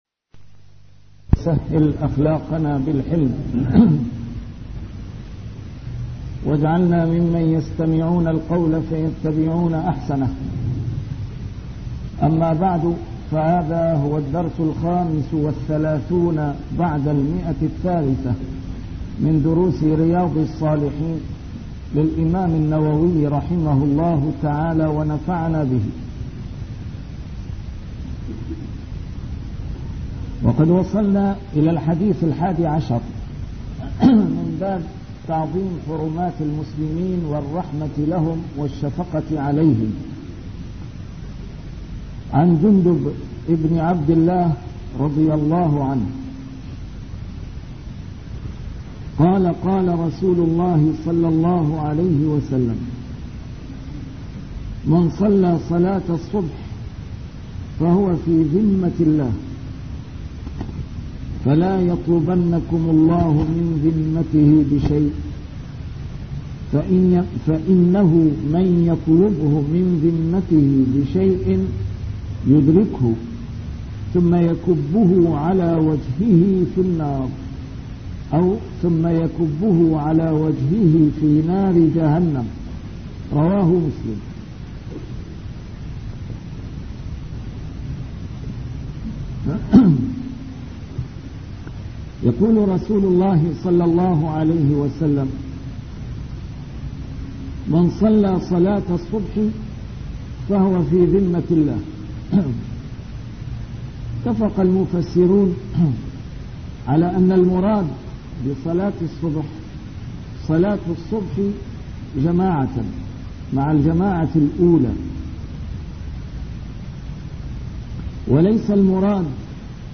شرح كتاب رياض الصالحين - A MARTYR SCHOLAR: IMAM MUHAMMAD SAEED RAMADAN AL-BOUTI - الدروس العلمية - علوم الحديث الشريف - 335- شرح رياض الصالحين: تعظيم حرمات المسلمين